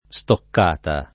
[ S tokk # ta ]